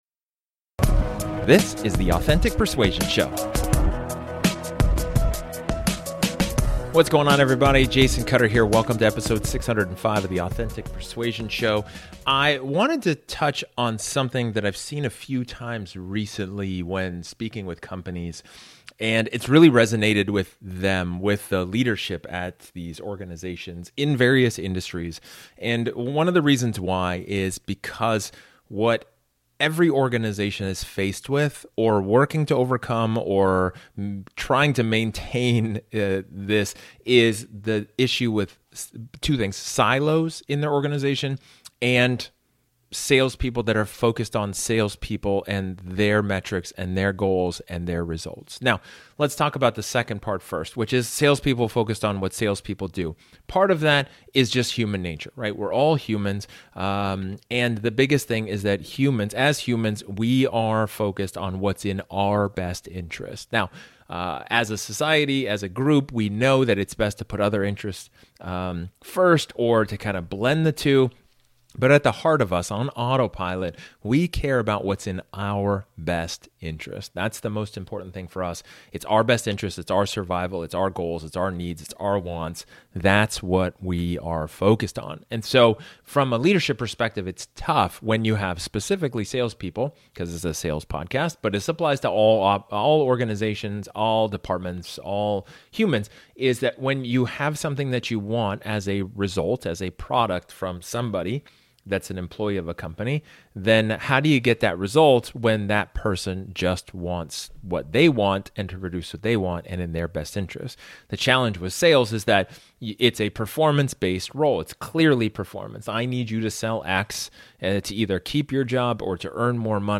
Silos can create barriers and hinder the overall effectiveness and efficiency of the sales organization. In this solo episode, I talk about how to get rid of silos.